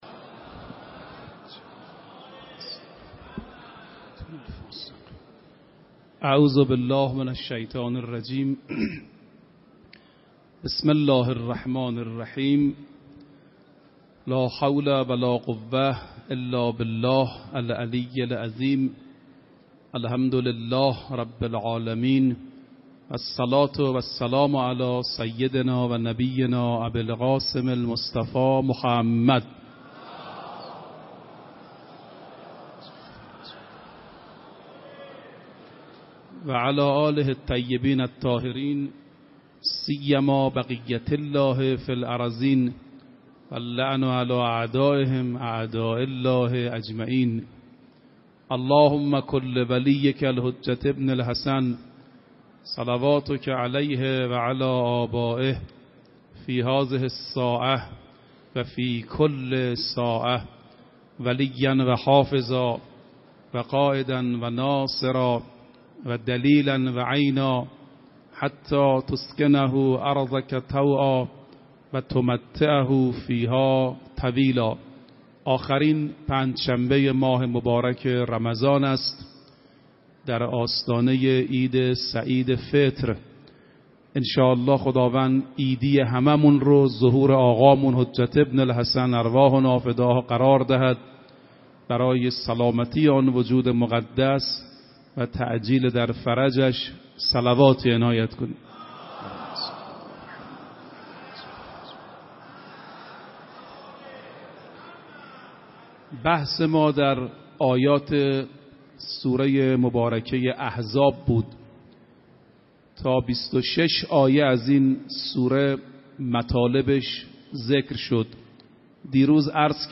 روز بیست و هفتم رمضان 96 - حرم حضرت معصومه - سخنرانی